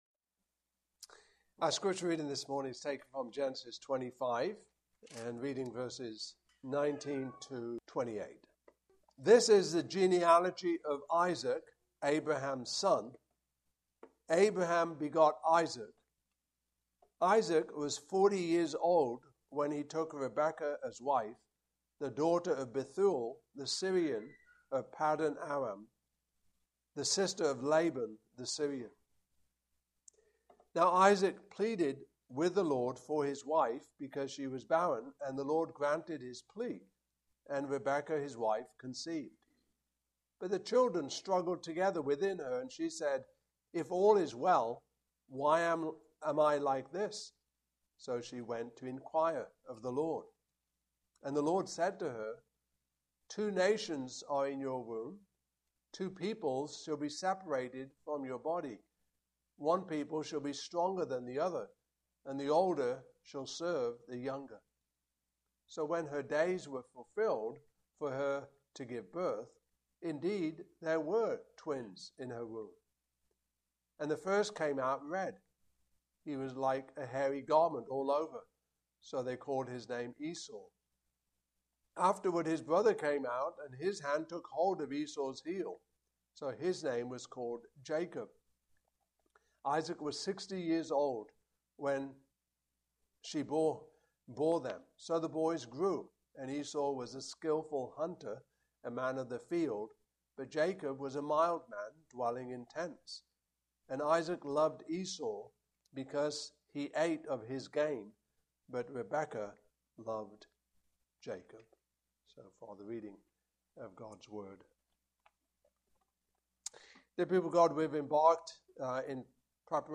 Passage: Genesis 25:19-28 Service Type: Morning Service